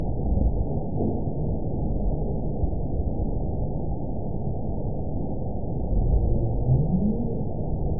event 917787 date 04/16/23 time 22:52:25 GMT (2 years ago) score 8.45 location TSS-AB04 detected by nrw target species NRW annotations +NRW Spectrogram: Frequency (kHz) vs. Time (s) audio not available .wav